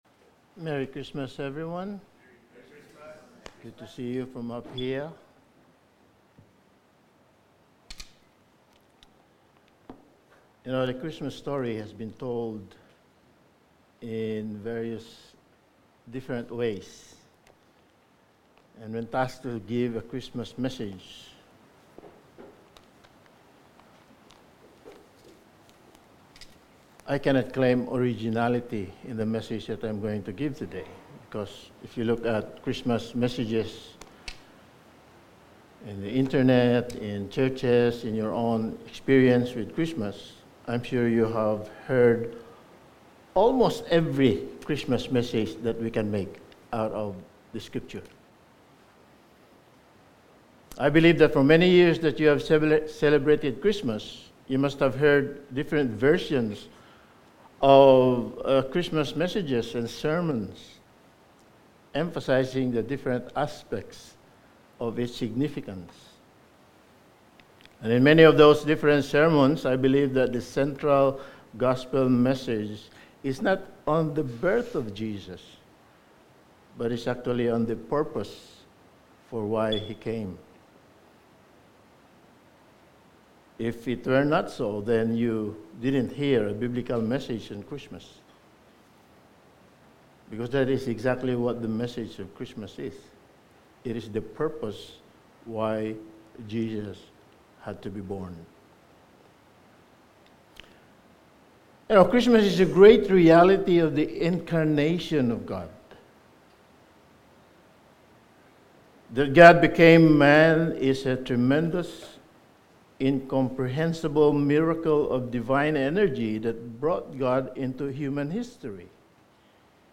Series: Topical Sermon
Matthew 2:1-6,11 Service Type: Special Event Christmas Day « The Day of the Lord